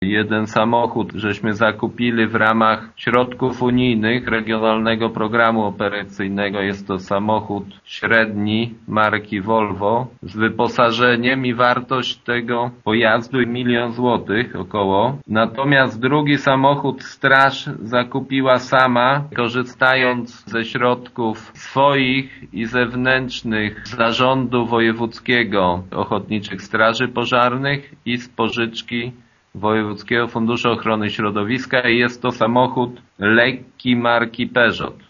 „Zakup obydwu samochodów był możliwy dzięki środkom, które gmina pozyskała z funduszy unijnych, a straż otrzymała z Zarządu Wojewódzkiego OSP i funduszu ochrony środowiska” – informuje wójt Gminy Głusk Jacek Anasiewicz: